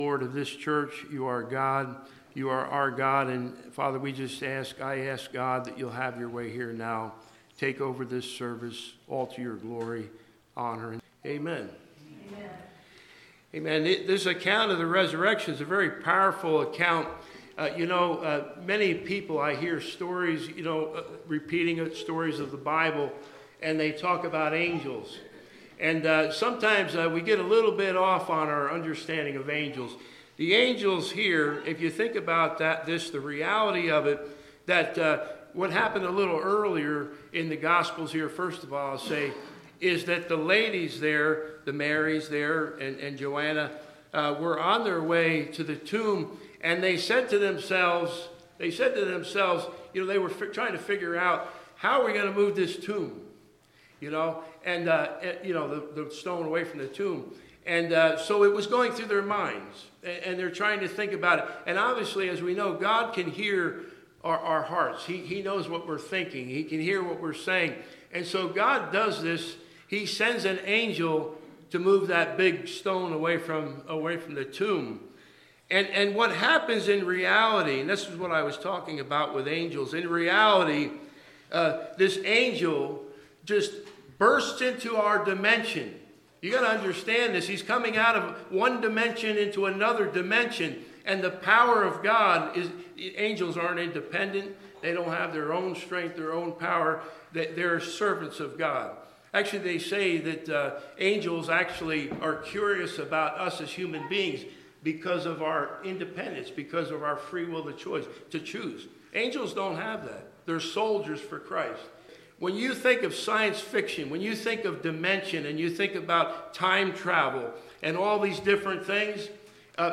Worship Service – April 20, 2025 – Happy Easter!!! He is Risen!!!